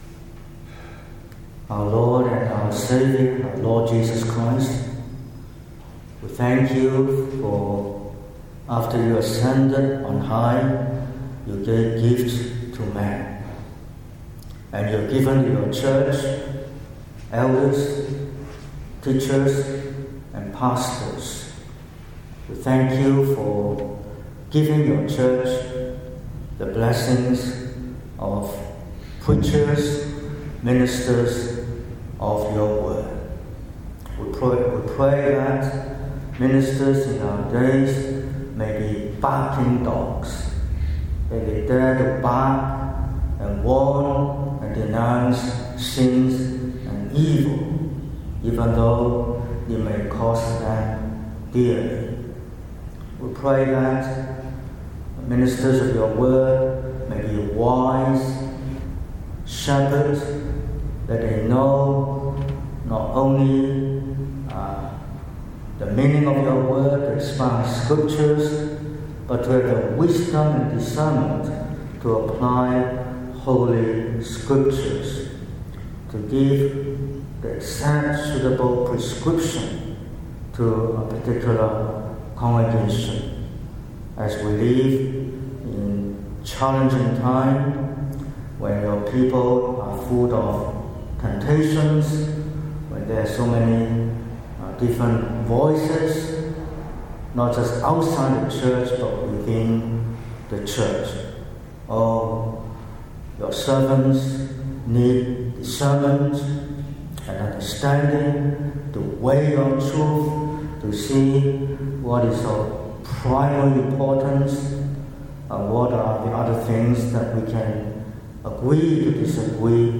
19/10/2025 – Evening Service: Marks of the justified – Isaiah